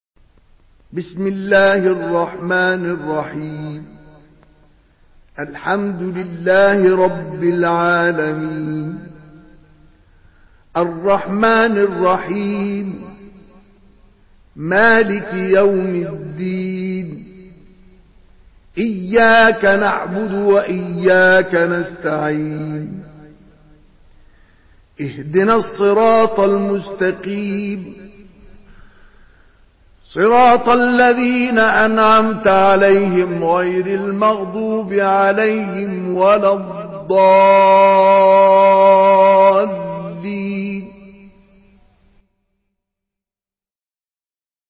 Surah Al-Fatihah MP3 in the Voice of Mustafa Ismail in Hafs Narration
Surah Al-Fatihah MP3 by Mustafa Ismail in Hafs An Asim narration.
Murattal Hafs An Asim